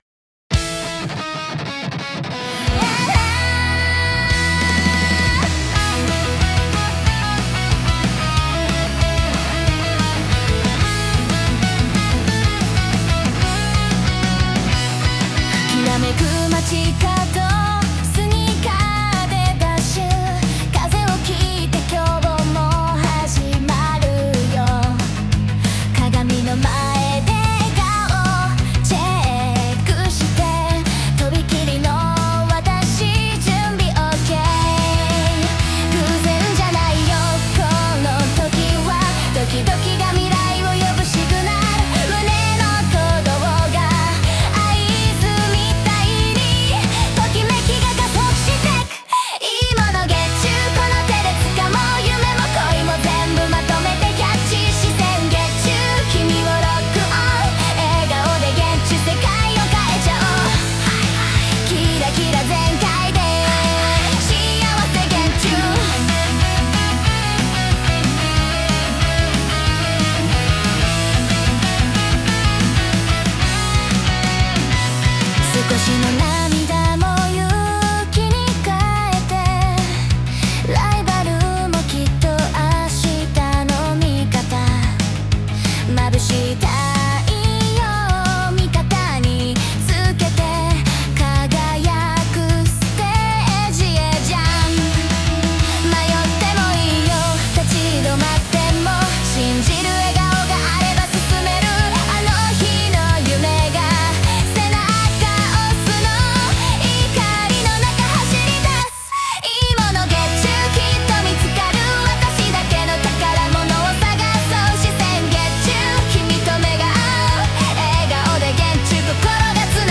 ステージのきらめきを感じるアイドルチューン